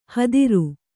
♪ hadiru